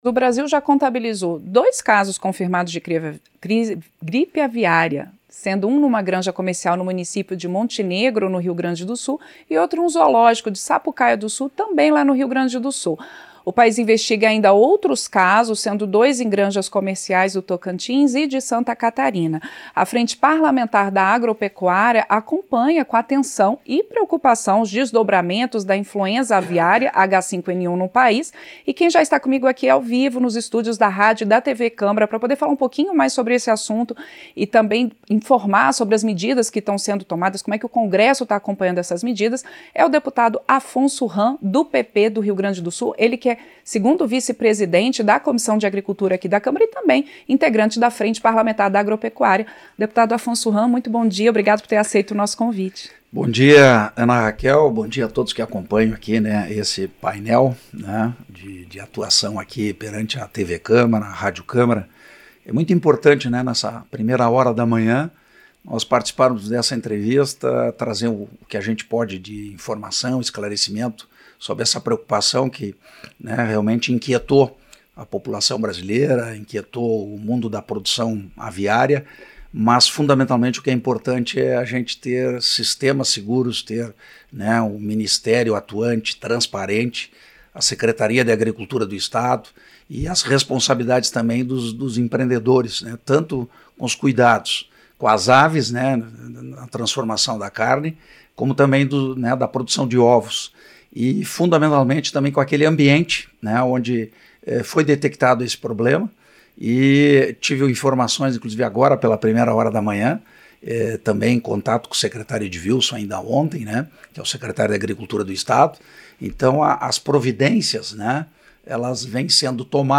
Entrevista - Dep. Afonso Hamm (PP-RS)